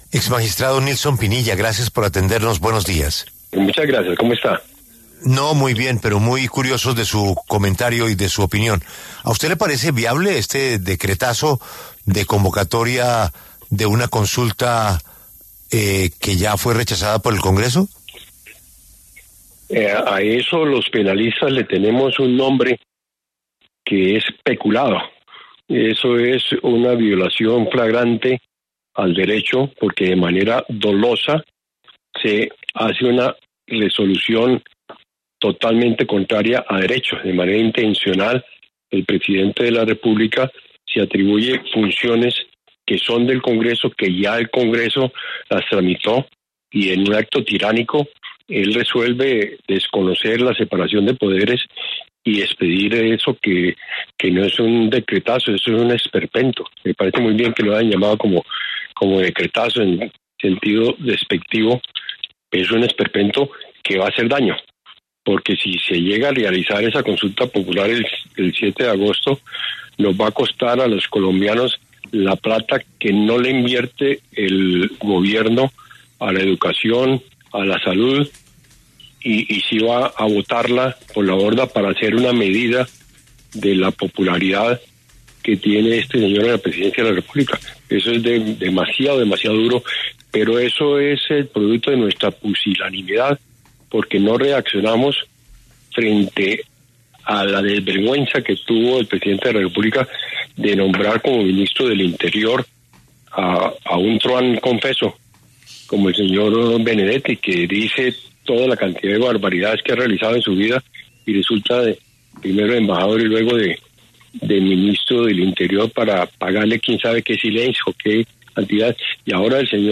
En entrevista con La W, el exmagistrado de la Corte Constitucional, Nilson Pinilla, arremetió duramente contra el decreto mediante el cual el gobierno nacional convocó a la consulta popular y lo calificó de “esperpento” y “desvergüenza” por parte del presidente Gustavo Petro y sus ministros.